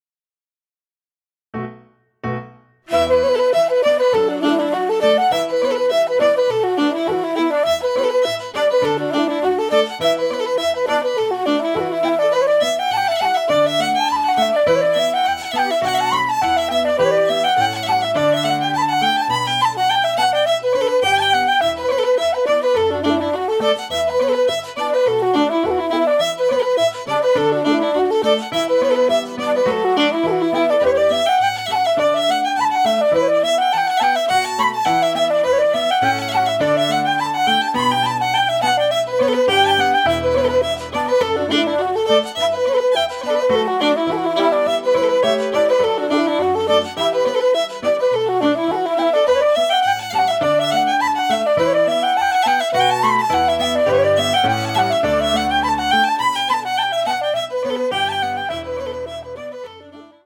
Reels 03:34